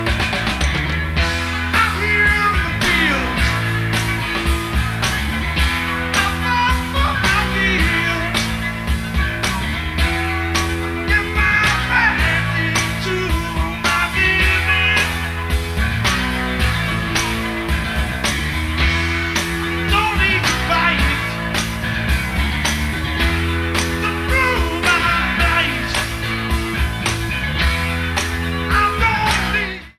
Pre-FM Radio Station Reels
Needs remastering.